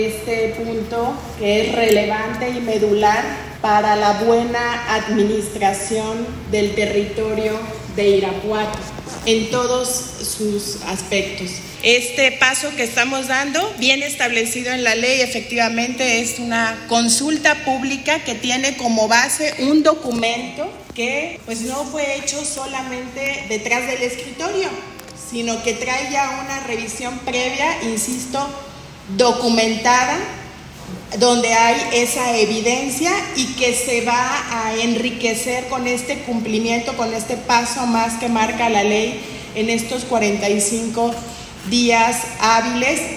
AudioBoletines